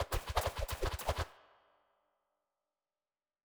Arrow Barrage Hold.wav